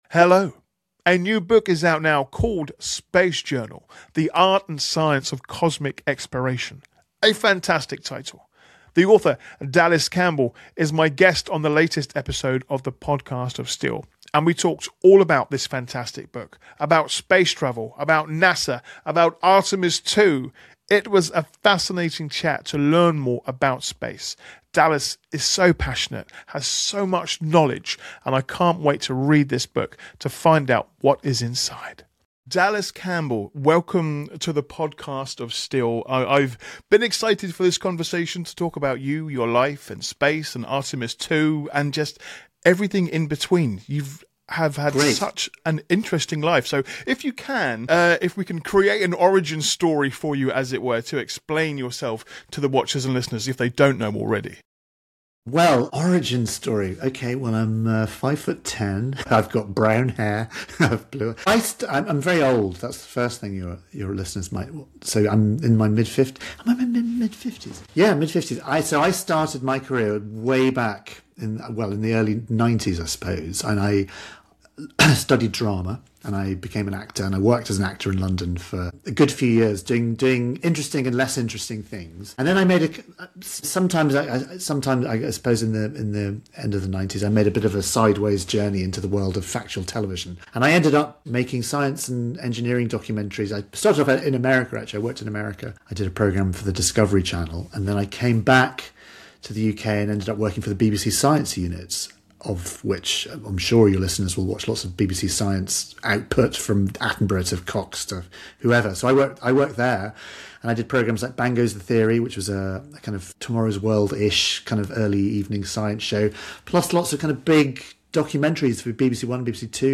We also look ahead to the future, chatting about the Moon, Mars, and NASA’s Artemis missions, and why space continues to inspire curiosity and wonder. It’s a fun, thoughtful conversation about exploration, creativity, and what drives us to look beyond our world.